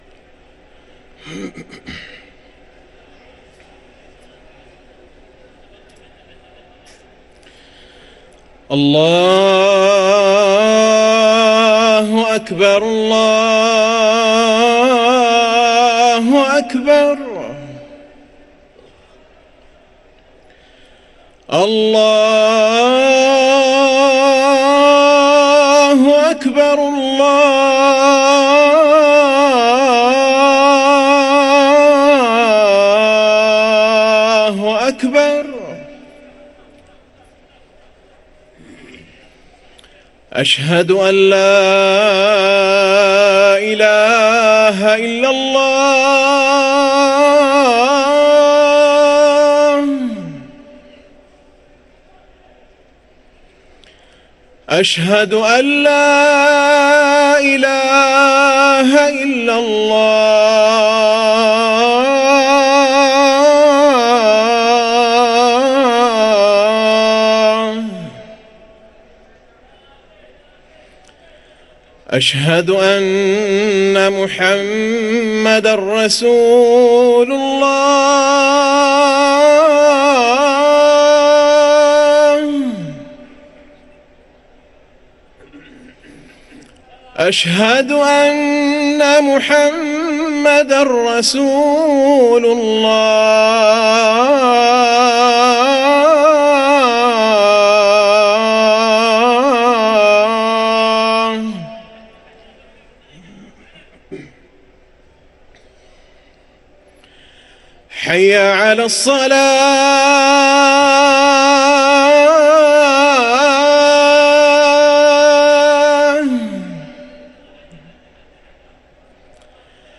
أذان العشاء